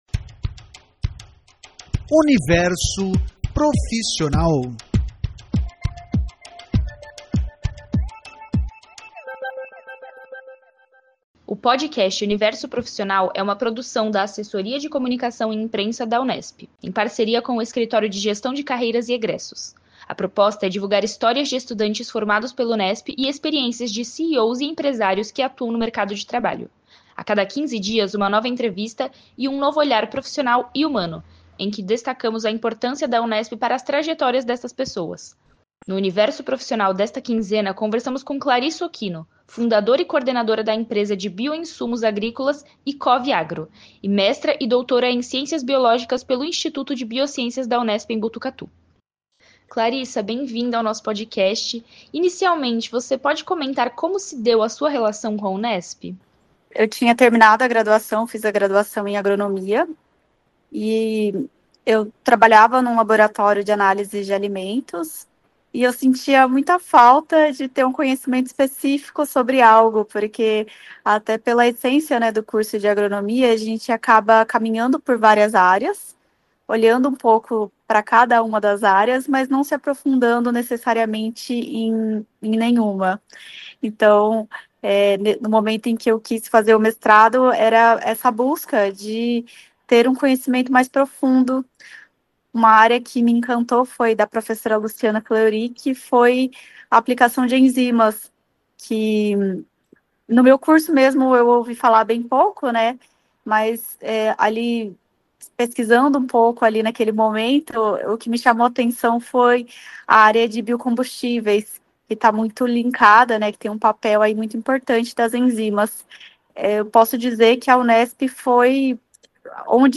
A proposta é divulgar histórias de estudantes formados pela Unesp e experiências de CEOs e empresários que atuam no mercado de trabalho. A cada quinze dias, uma nova entrevista e um novo olhar profissional e humano, em que destacamos a importância da Unesp para as trajetórias destas pessoas.